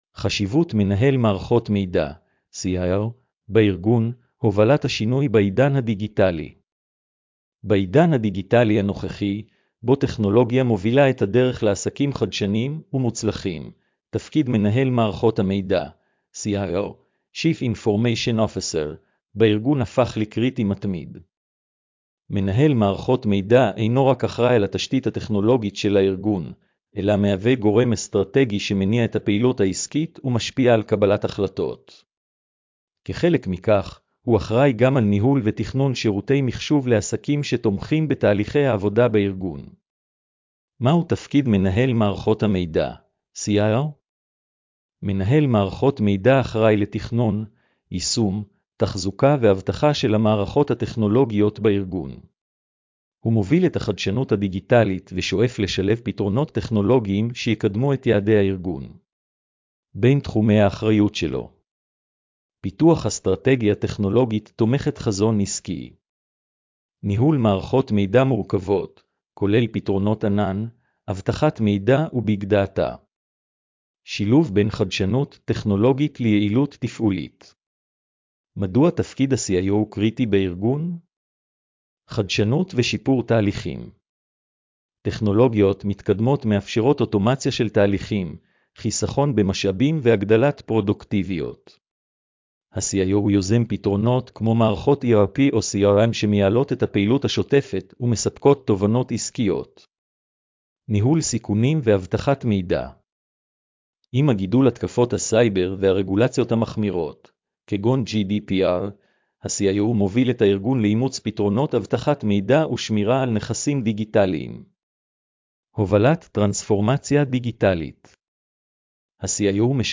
השמעת המאמר לכבדי ראייה